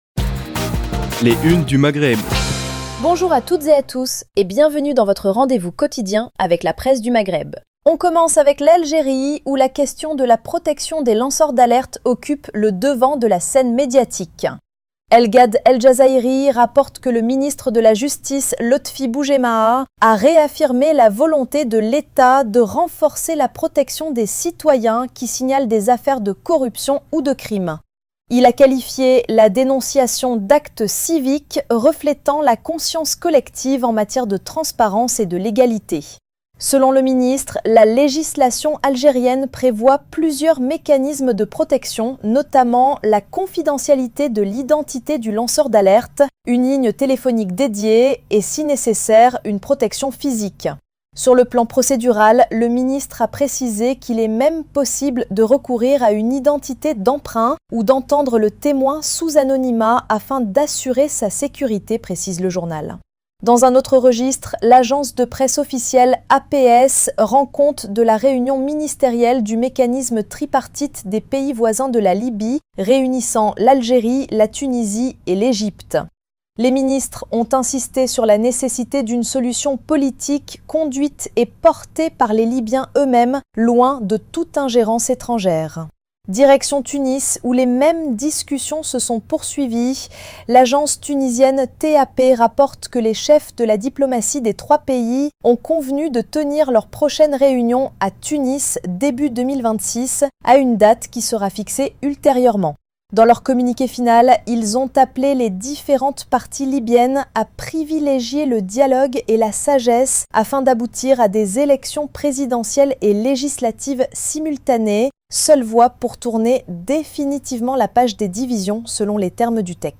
Revue de presse des médias du Maghreb